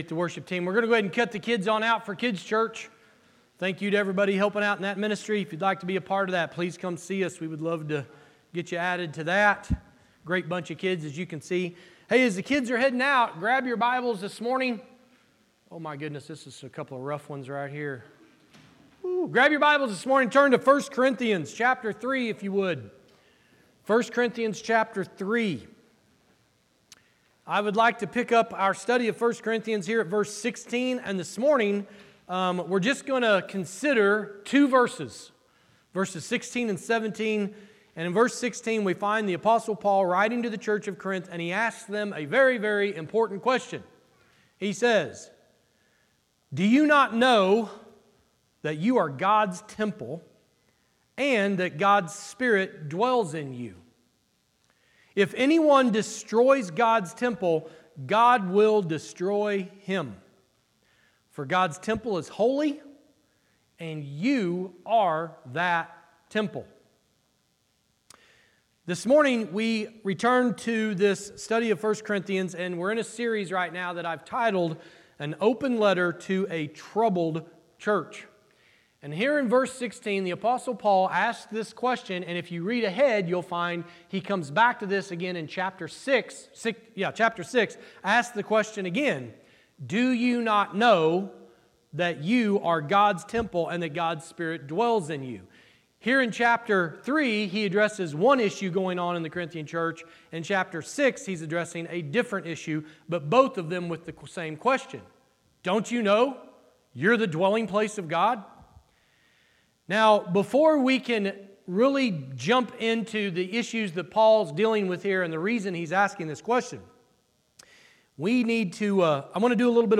Church in Action Sermon Podcast